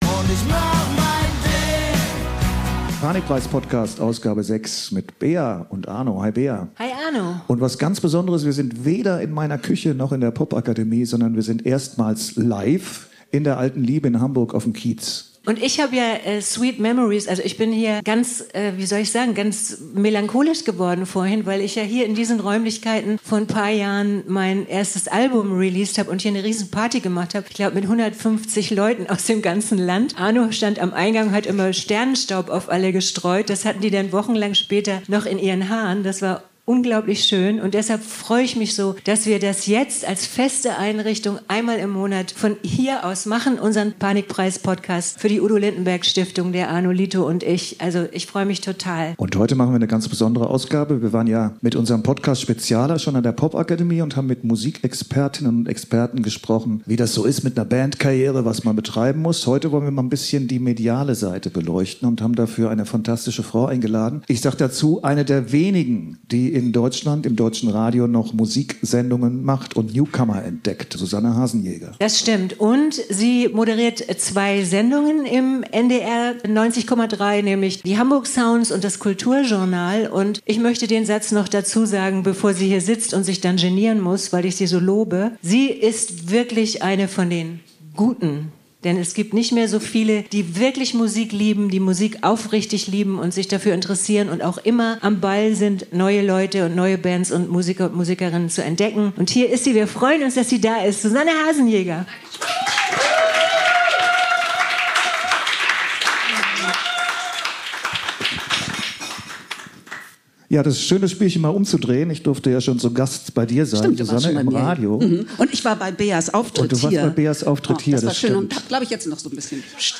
Erstmals hört Ihr uns live aus der Alten Liebe in Hamburg.